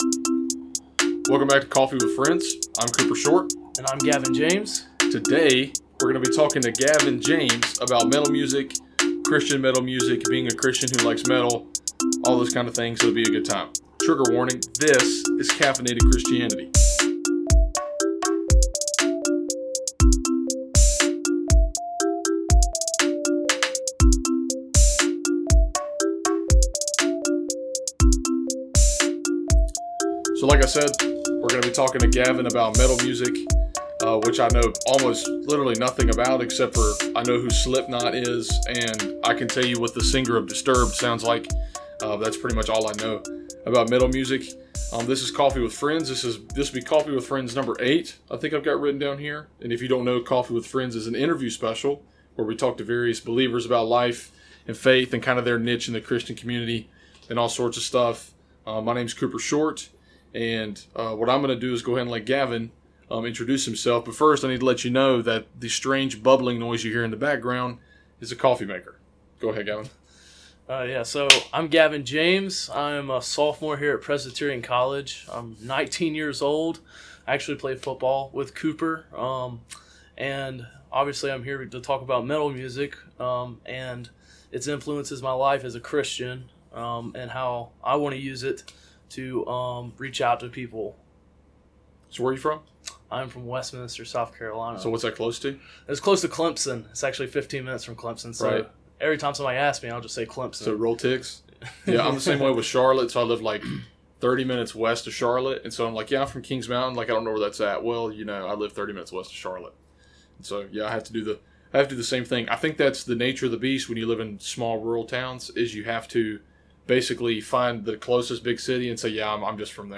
On this installment of Coffee With Friends, for one, there is actually coffee being consumed during the recording.